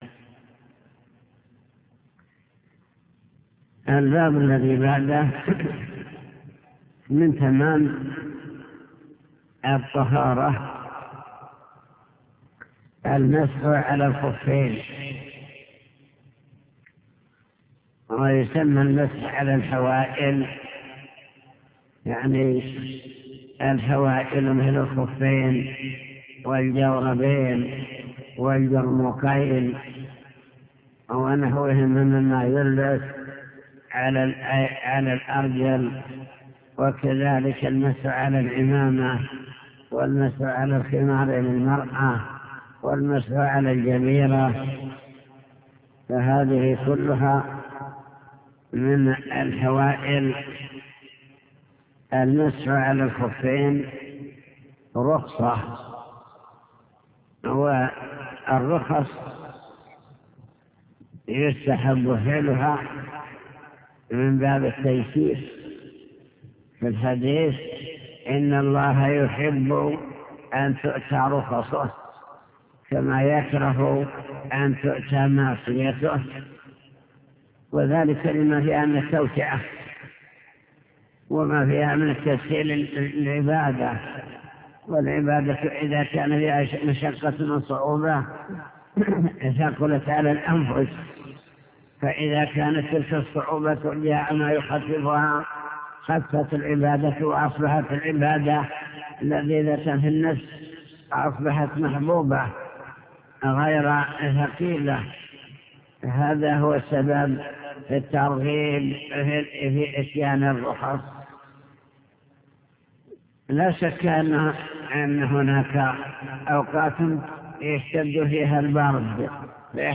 المكتبة الصوتية  تسجيلات - كتب  شرح كتاب دليل الطالب لنيل المطالب كتاب الطهارة المسح على الخفين